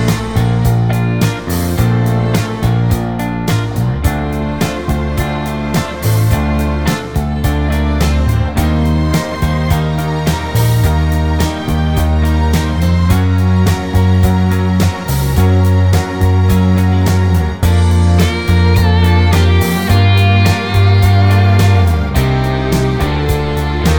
No Backing Vocals Soundtracks 3:41 Buy £1.50